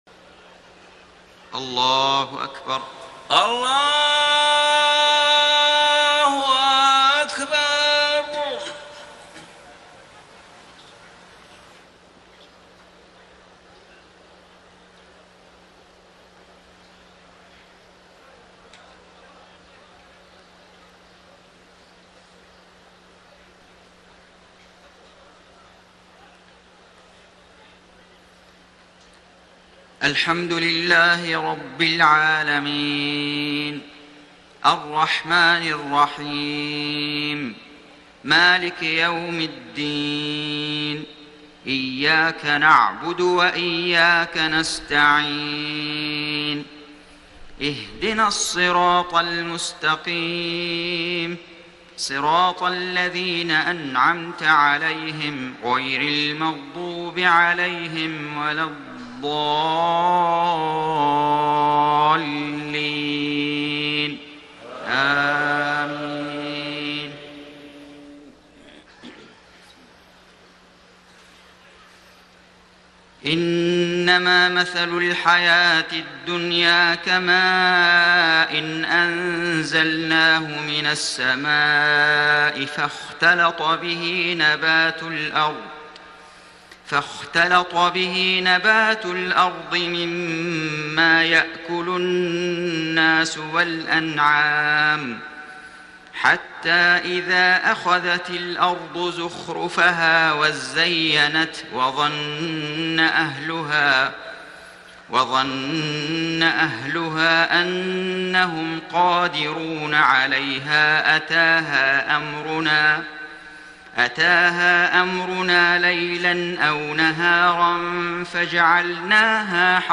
صلاة الفجر 11 ذوالحجة 1431هـ من سورتي يونس 24-30 و الحديد 18-20 > 1431 🕋 > الفروض - تلاوات الحرمين